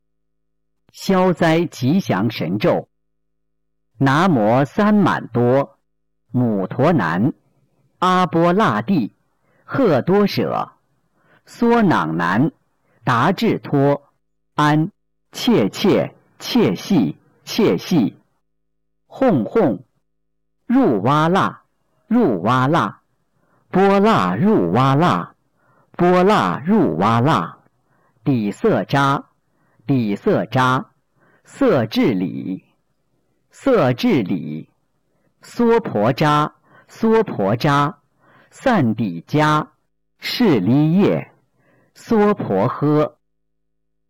008《消灾吉祥神咒》男声
目录：佛教经文